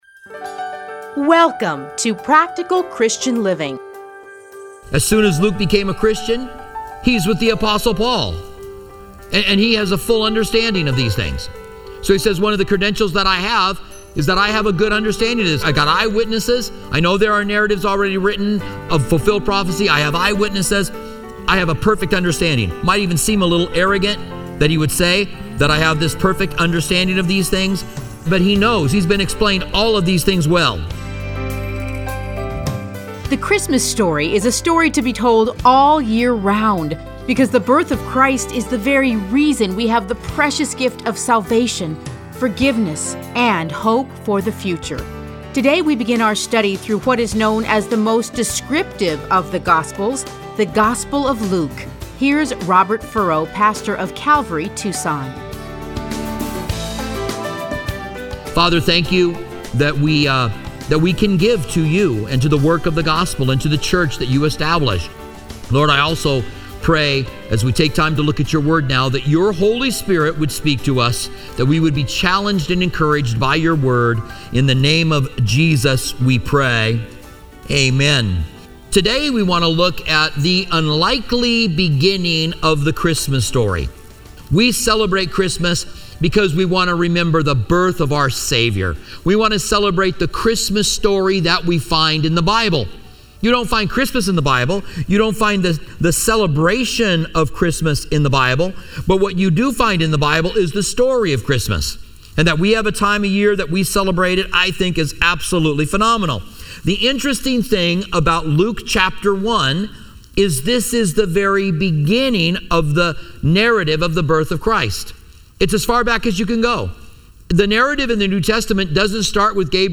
Listen to a teaching from Luke 1:1-25.